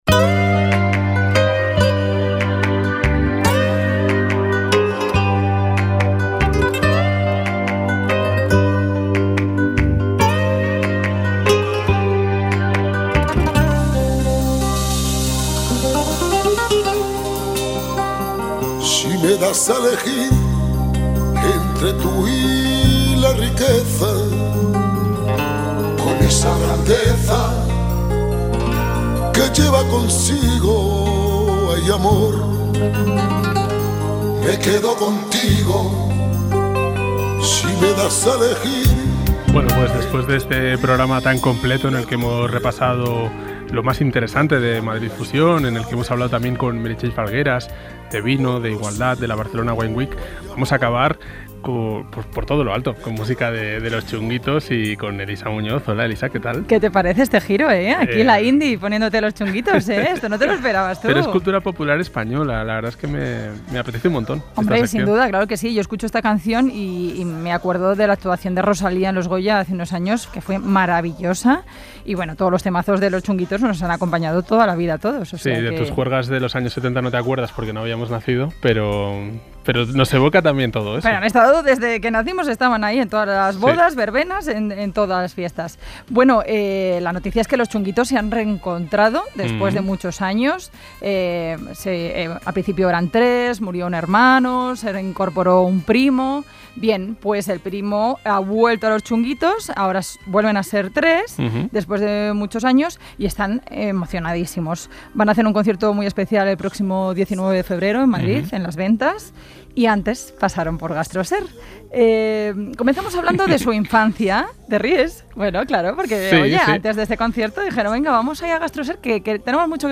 Juntos recuerdan sus duros inicios, su paso por MasterChef y hablan de las ganas que tienen de pasar por un restaurante en Badajoz cuando comiencen la gira. Todo, con mucho humor.